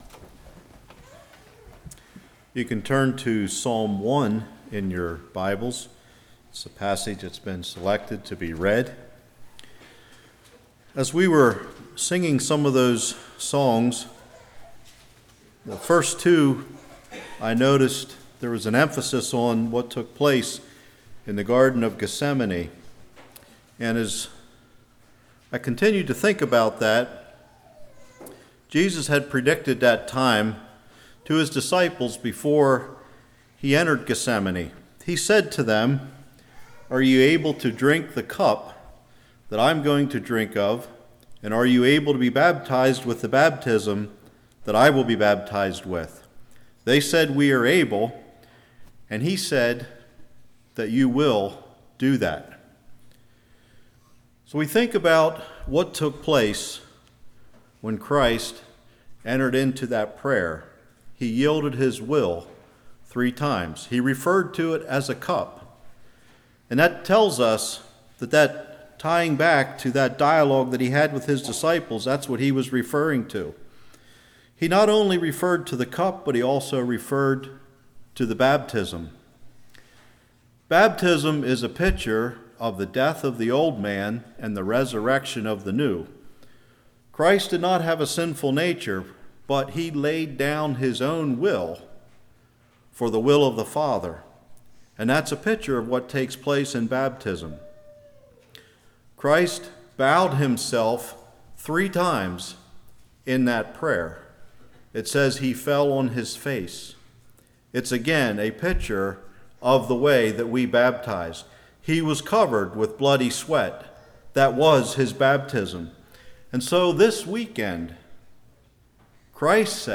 Series: Spring Lovefeast 2017
Service Type: Evening